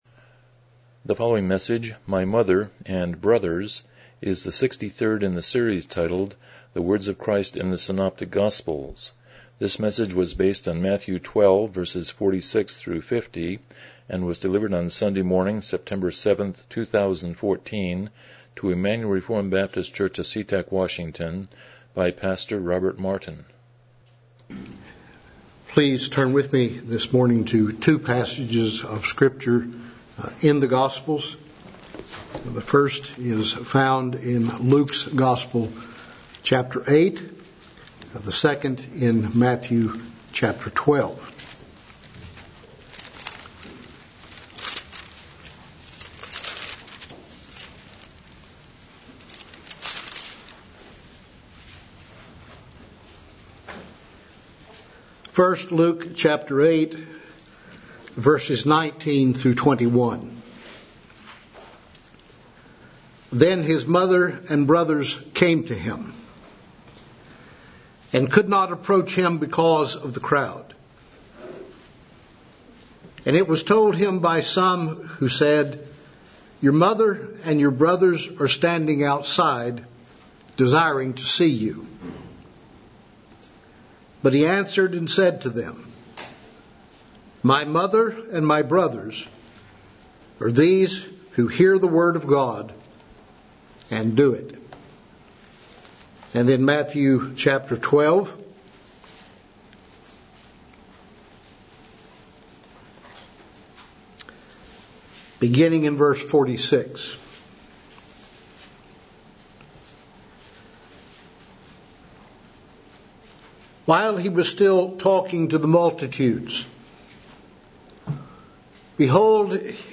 Passage: Matthew 12:46-50 Service Type: Morning Worship